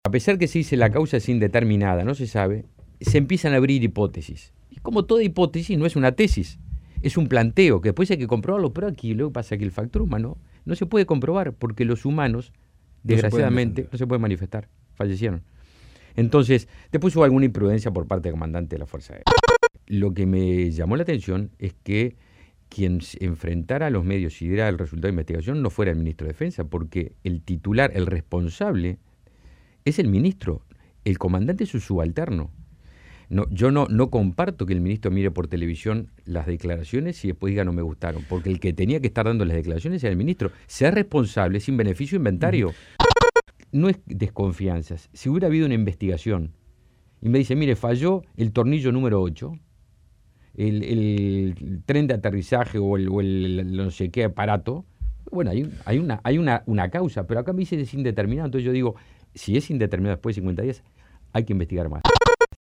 El senador nacionalista Javier García, entrevistado hoy en la Mañana de Espectador dijo que se necesita una investigación externa para determinar las causas del accidente y reclamó que el ministro Menéndez se haga cargo de la situación.